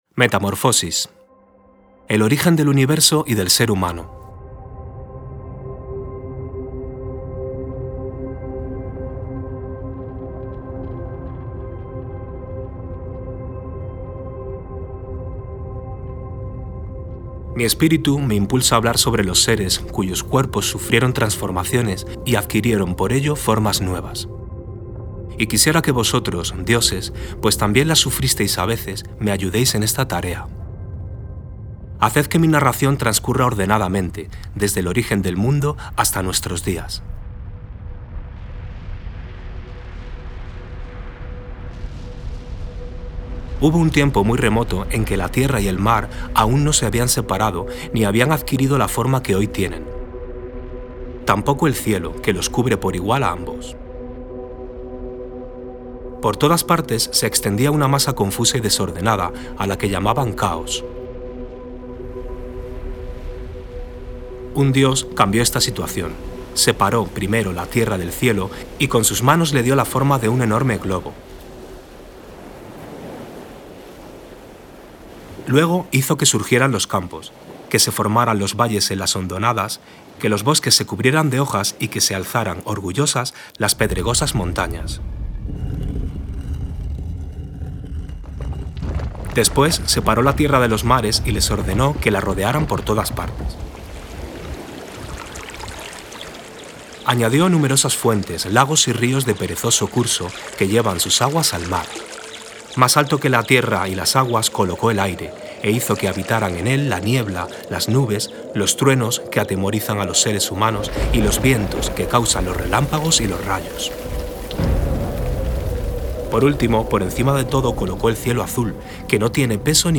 Lectura inicial. Metamorfosis
13_lectura_metamorfosis.mp3